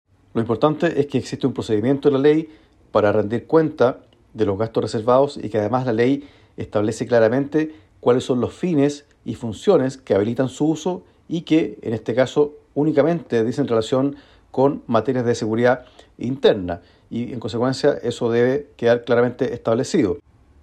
El diputado del Partido Comunista, Luis Cuello, por su parte, resaltó los procedimientos que existen para aclarar en qué se utilizan estos gastos reservados.